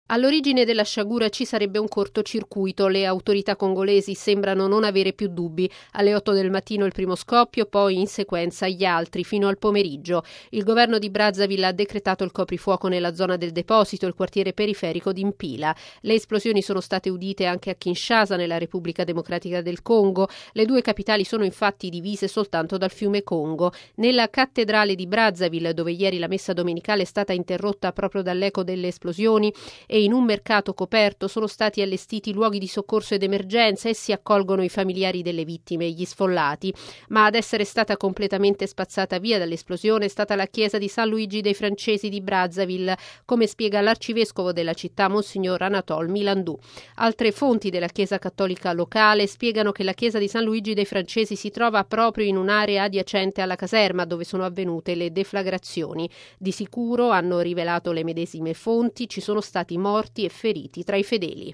◊   E' salito ad almeno 146 morti, centinaia di feriti e numerosi edifici distrutti il bilancio, purtroppo ancora provvisorio, delle esplosioni avvenute ieri in un deposito di munizioni a Brazzaville, capitale della Repubblica del Congo. Il servizio